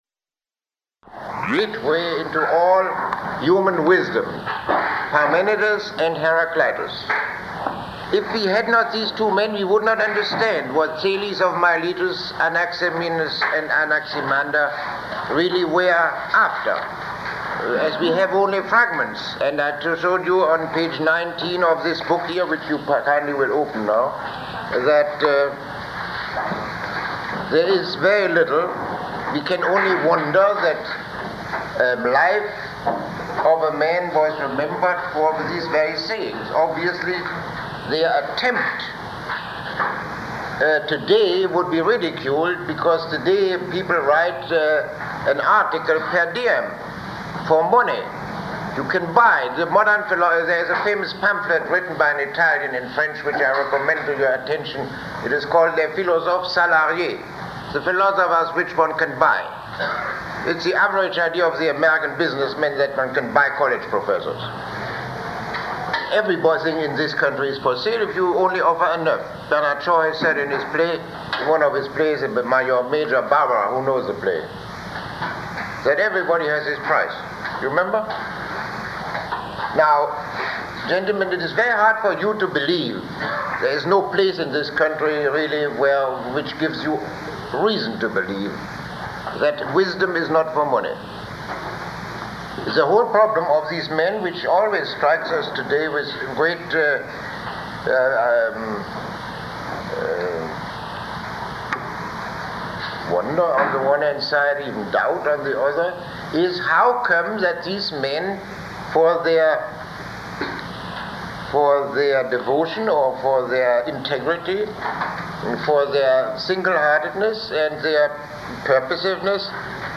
Lecture 09